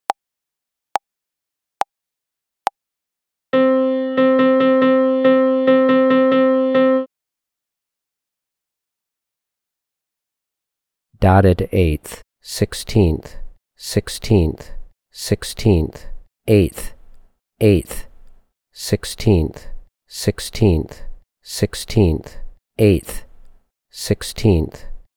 • Level 10: Triplet and Sixteenth Note Rhythms in 4/4.
Find examples below for each level of the voice answer MP3s:
Rhy_ET_L10_70BPM-1.mp3